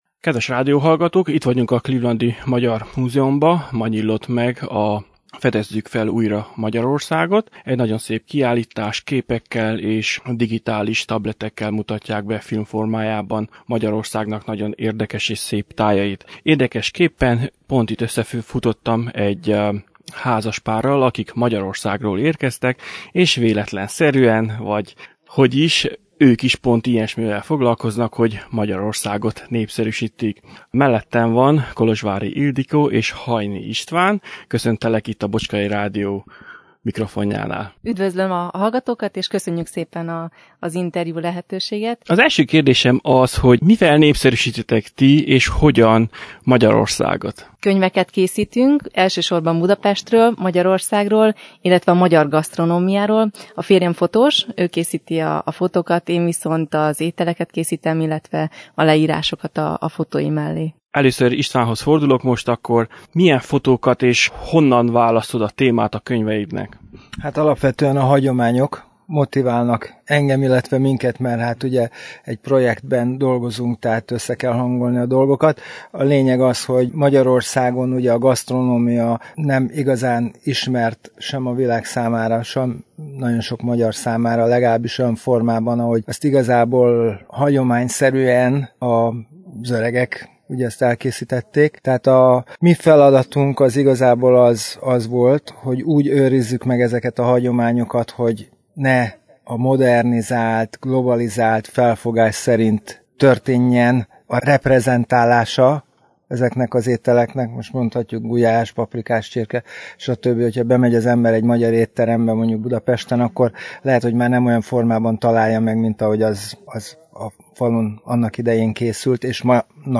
Természetesen a kiadó munkatársaival is készítettem egy interjút, amit meghallgathatnak az alábbiakban.